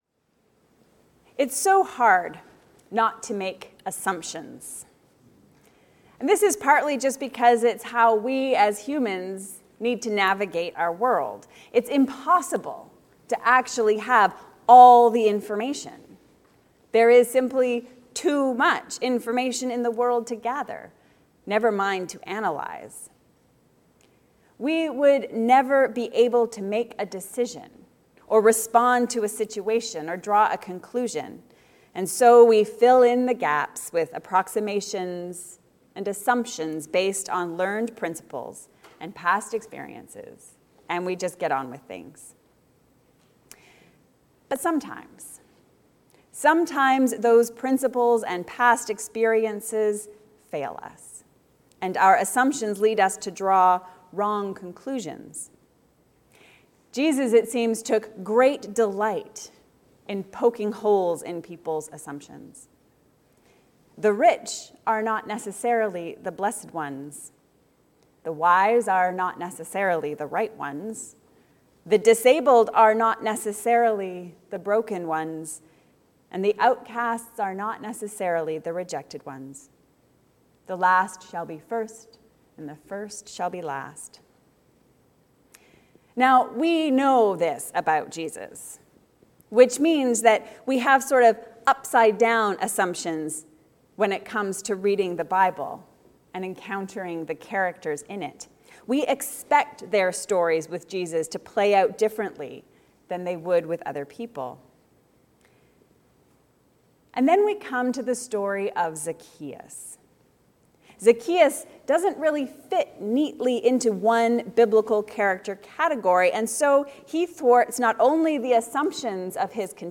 Zacchaeus – more than meets the eye? A sermon on Luke 19:1-10